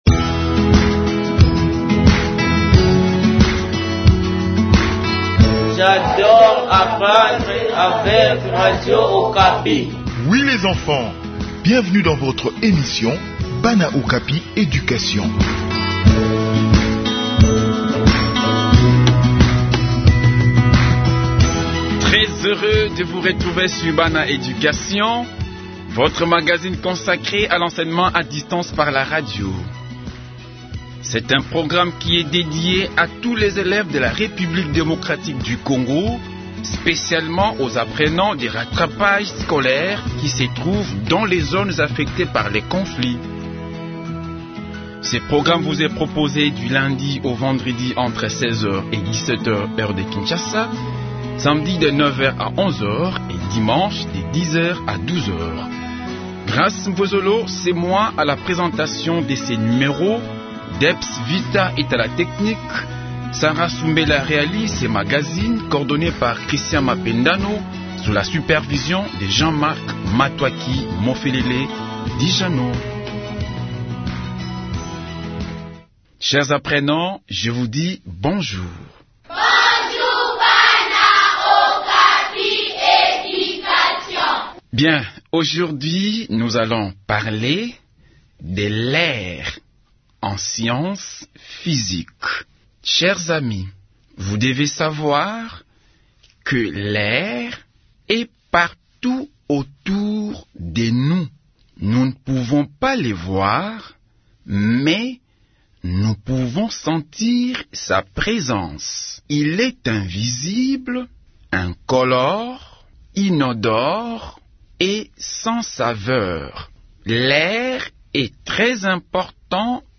Enseignement à distance : leçon de physique sur l’air